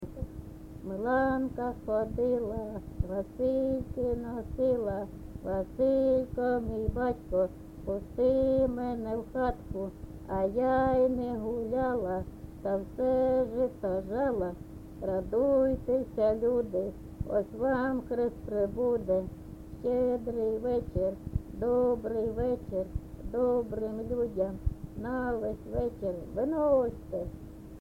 ЖанрЩедрівки
Місце записус. Хрестівка, Горлівський район, Донецька обл., Україна, Слобожанщина